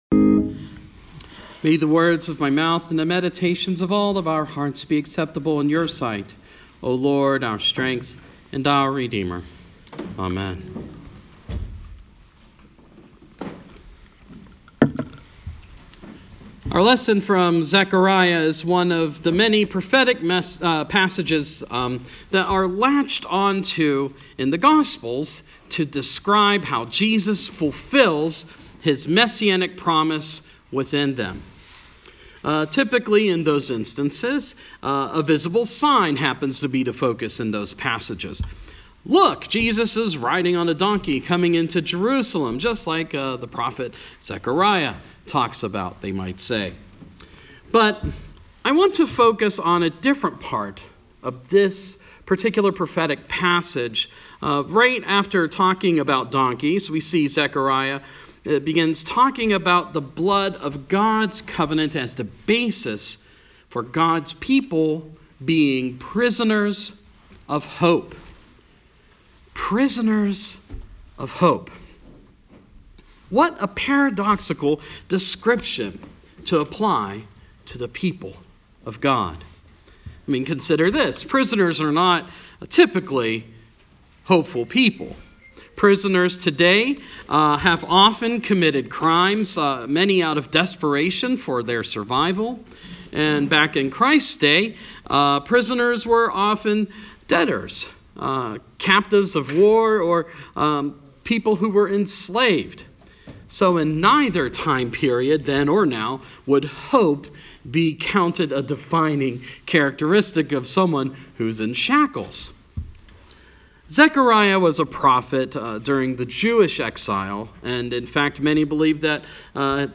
palm-sunday-2018.mp3